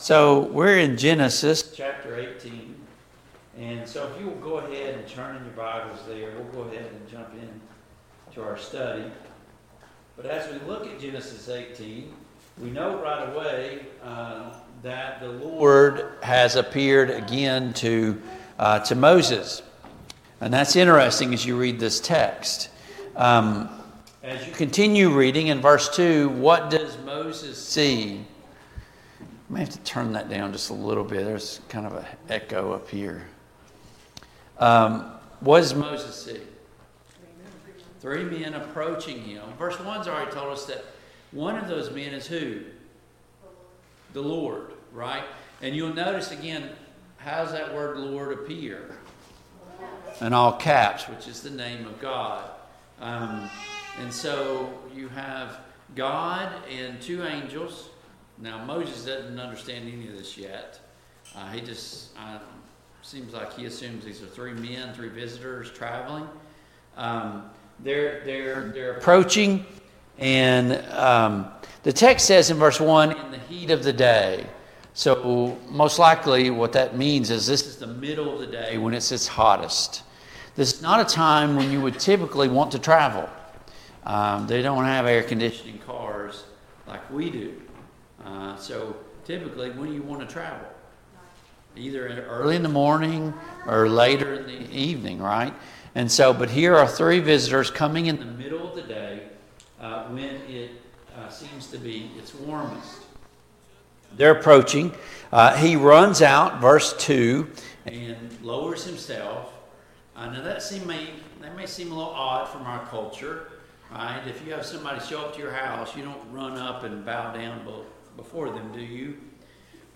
Passage: Genesis 18, Genesis 19 Service Type: Family Bible Hour